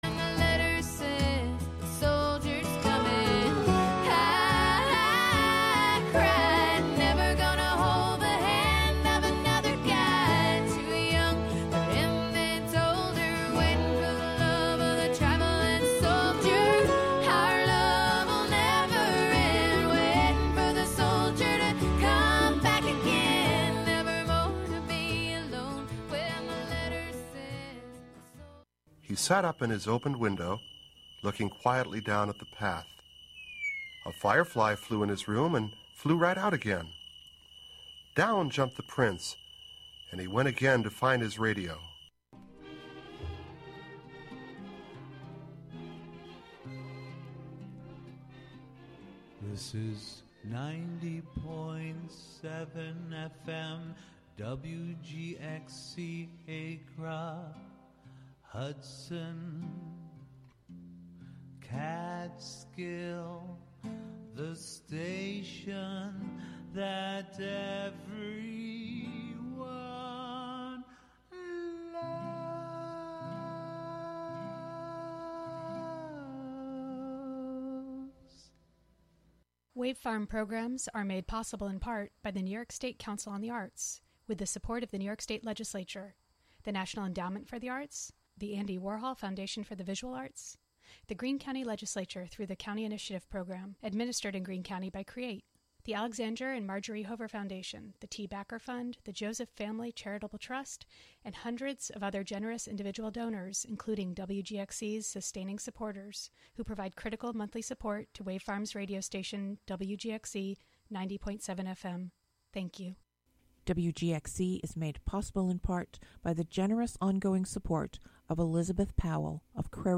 Monthly excursions into music, soundscape, audio document and spoken word, inspired by the wide world of performance. Live from Ulster County.